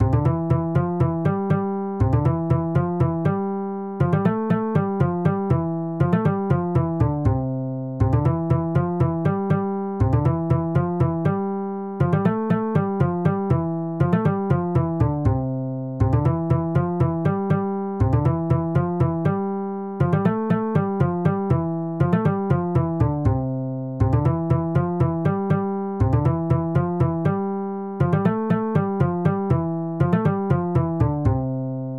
Проиграть мелодию: